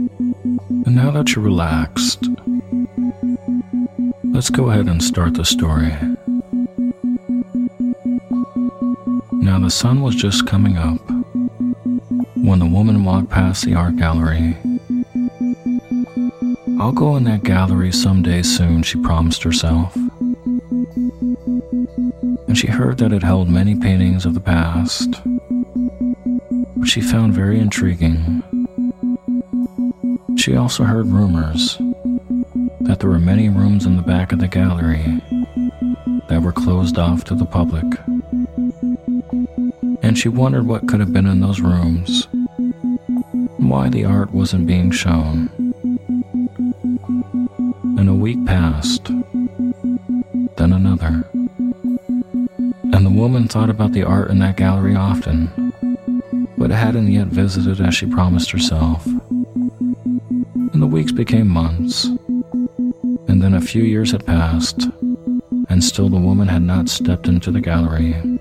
Story Based Meditation "The Art Gallery" With Isochronic Tones
In this story based meditation, you’ll be guided using a story about an art gallery getting renovated. This is a great meditation for long term goals that have a lot of challenges and barriers.